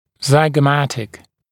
[ˌzaɪgə’mætɪk][ˌзайгэ’мэтик]скуловой